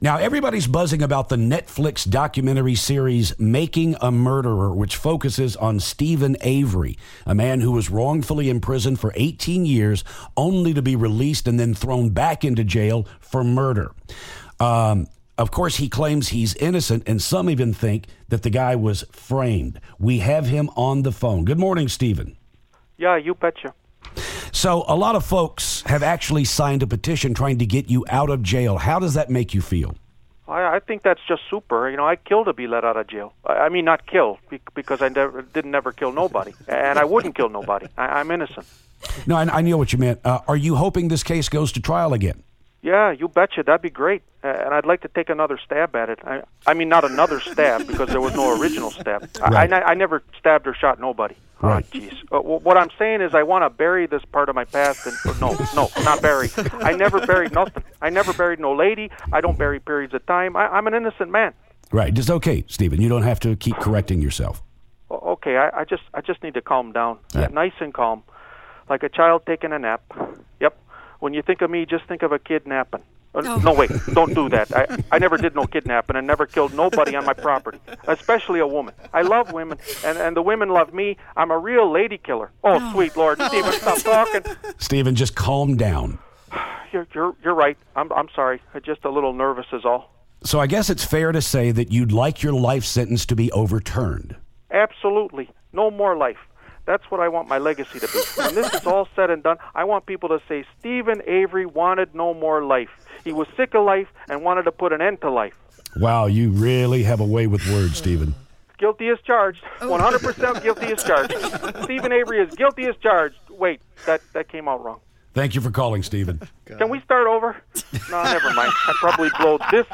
The guys get a very strange call from Steven Avery, the focus of the Netflix documentary "Making A Murderer."